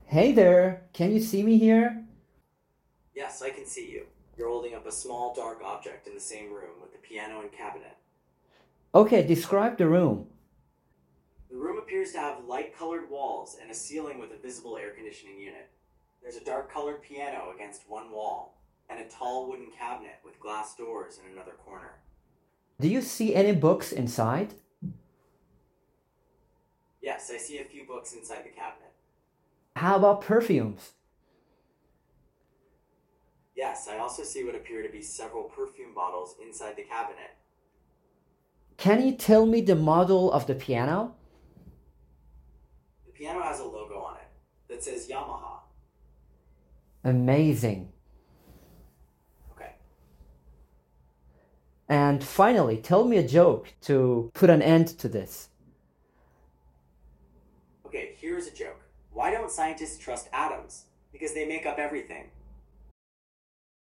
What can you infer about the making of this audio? Listen to my brief audio demonstration of Gemini 2.0 Flash. I used my laptop microphone and camera for this interaction.